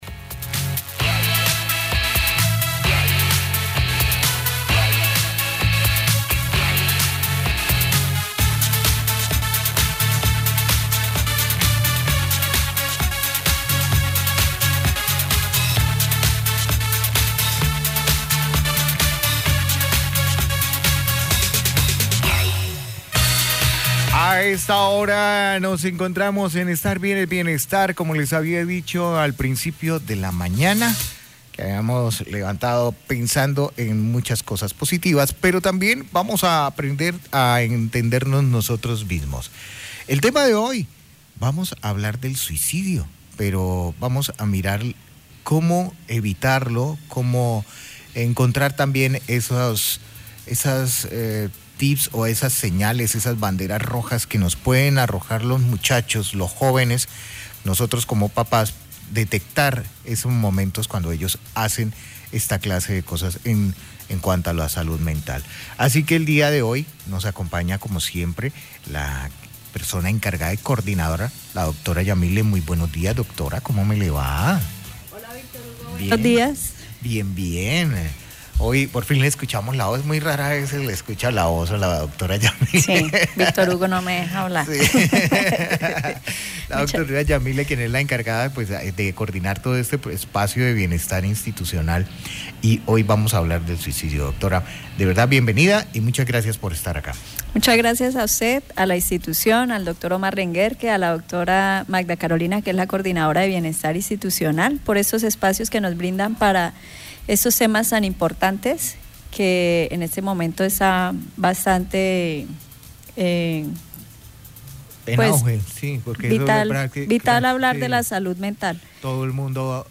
La conversación